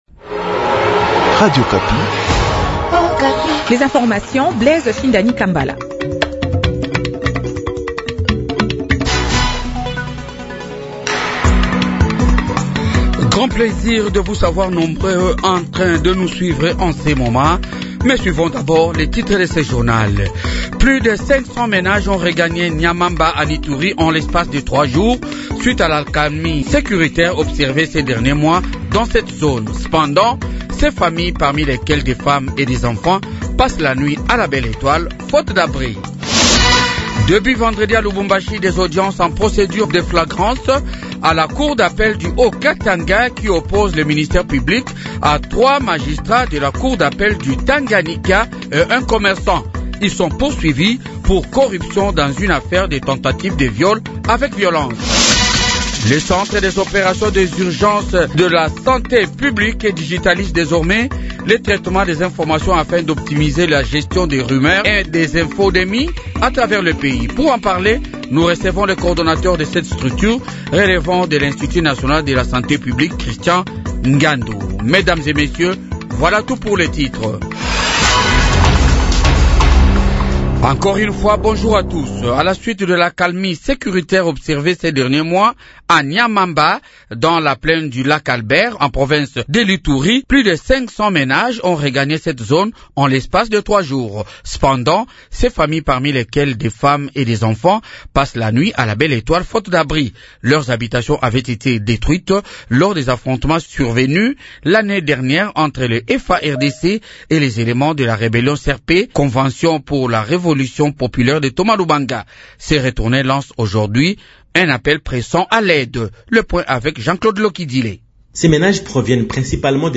Journal du matin 8h